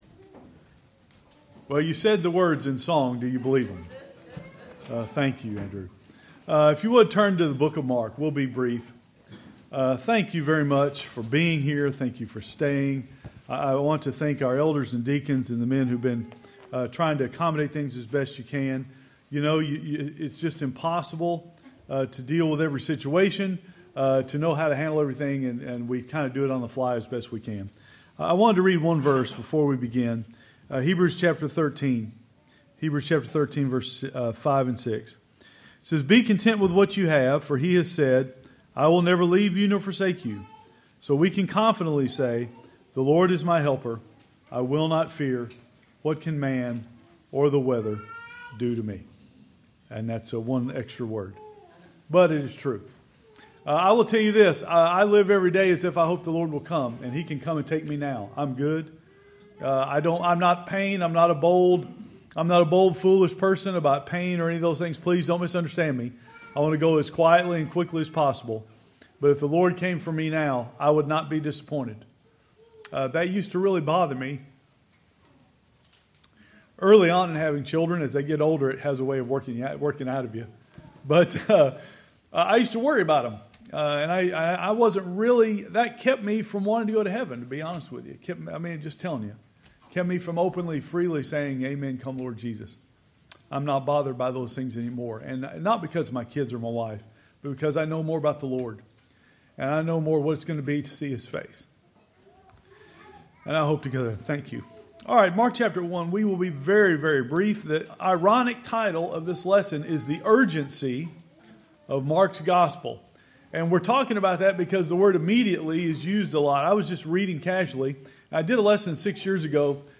This sermon was shortened due to severe weather in the area.